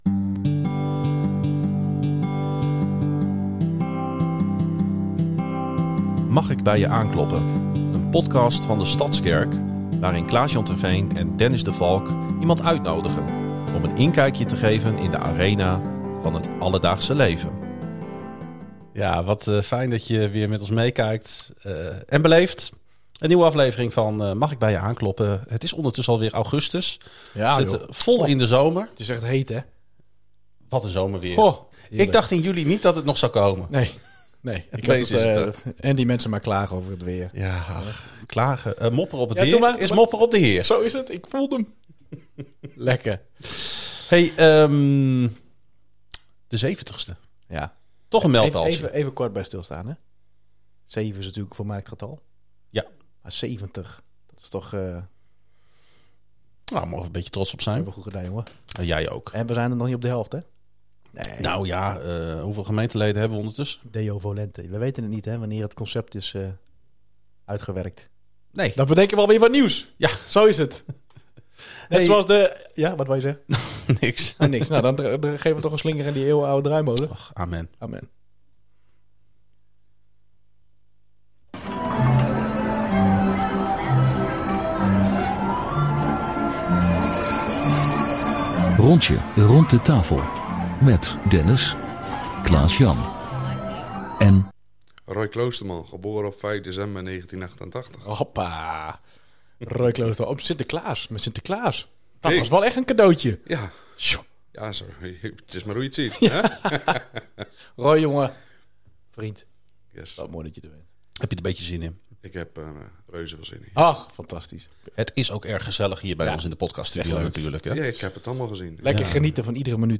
De gastheren kloppen aan bij gemeenteleden en gasten om samen in gesprek te gaan.